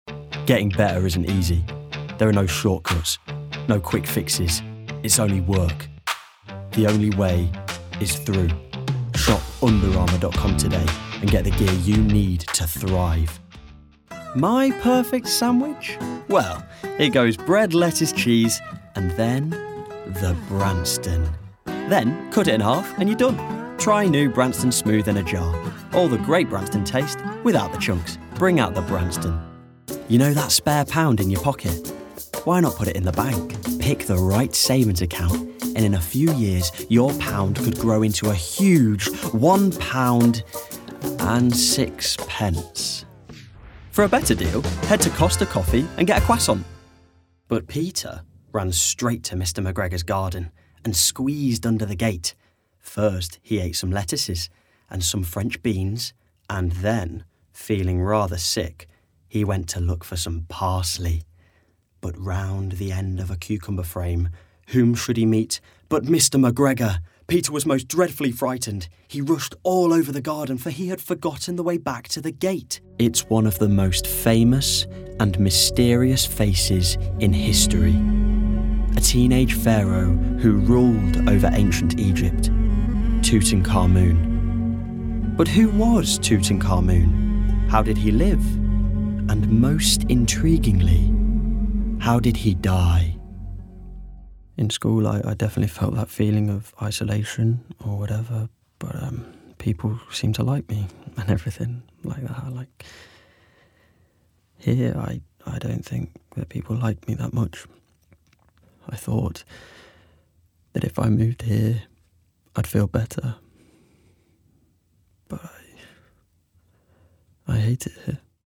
Native voice:
East Midlands
Voicereel: